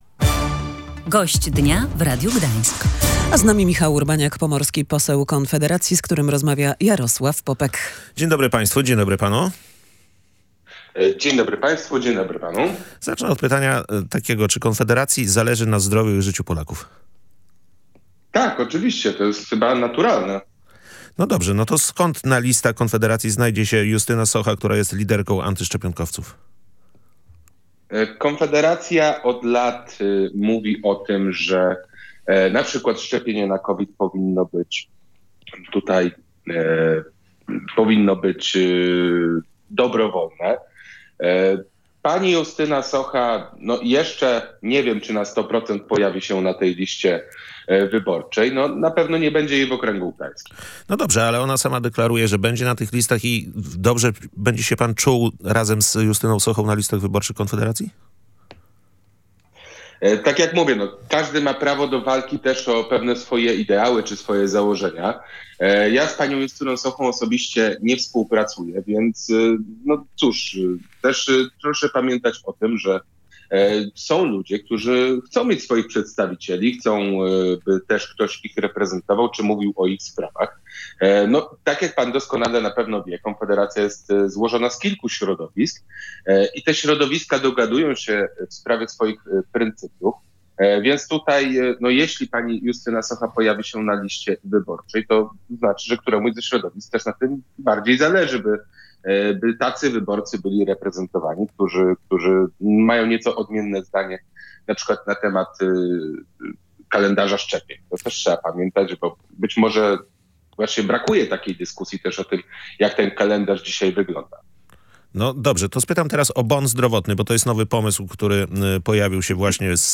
Na początku rozmowy „Gość Dnia Radia Gdańsk” został zapytany o to, czy Konfederacji zależy na zdrowiu i życiu Polaków.